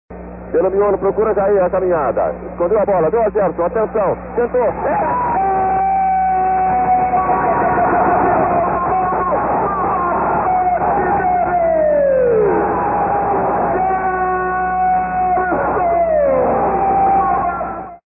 Fernando Solera narra o gol de Gerson contra a Italia(ele gritou "o melhor futebol do mundo no barbante deles" porque era transmissão em rede nacional, vários canais, não podia ser "o melhor futebol do mundo no 13")
FS_gol_gerson.mp3